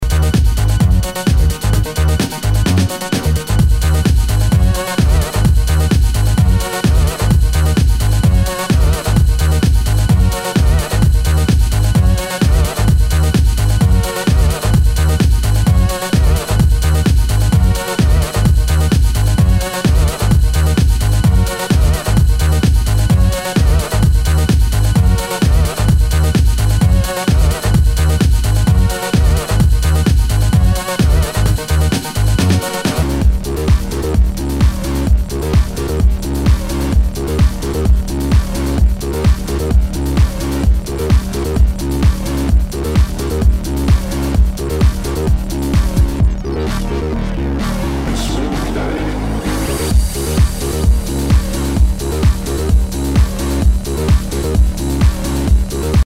HOUSE/TECHNO/ELECTRO
ナイス！ファンキー・エレクトロ・ハウス！